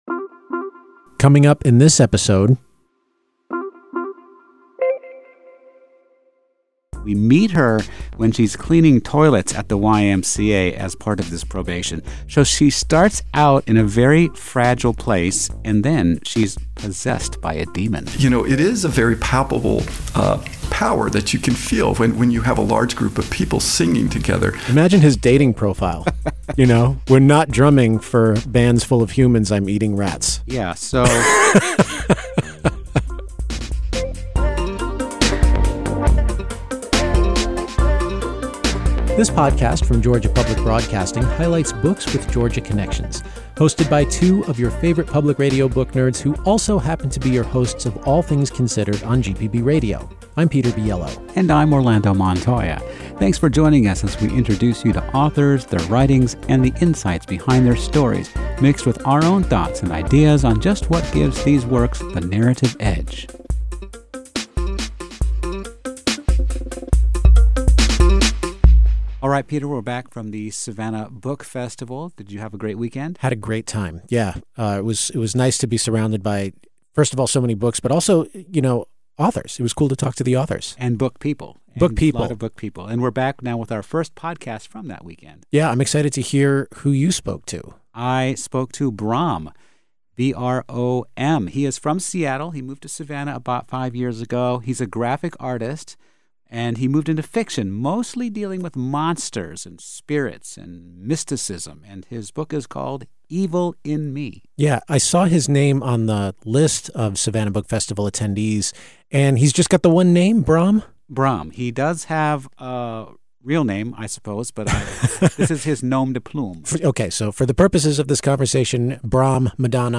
… continue reading 65 odcinków # Society # Books # Arts # Georgia Public Broadcasting # Lifestyle # Hobbies # Read # Review # NPR # GPB # Interviews # Authors